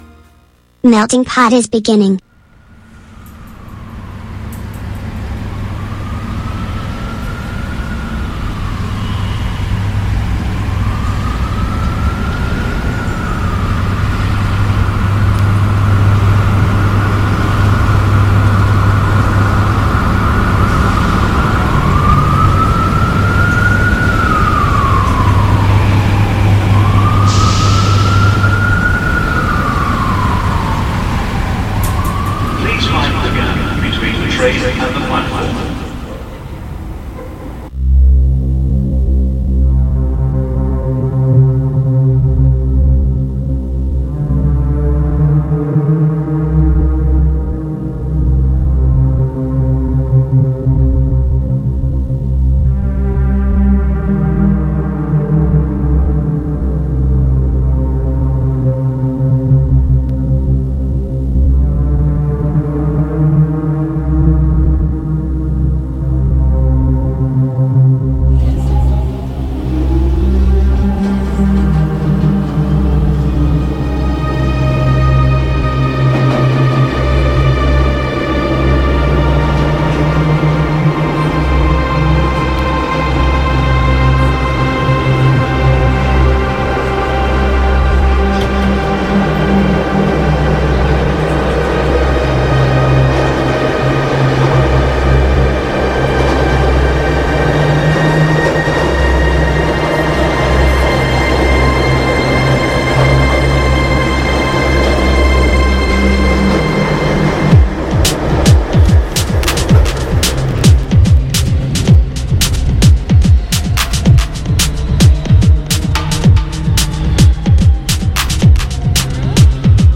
MUSICA NOTIZIE INTERVISTE A MELTINGPOT | Radio Città Aperta